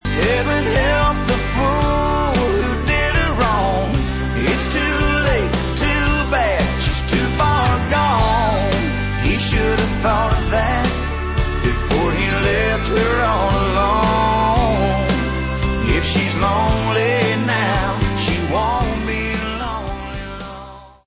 Tags: ringtones tones cell phone music melody country songs